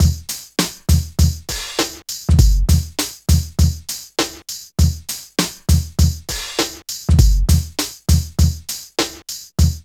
60 DRUM LP-L.wav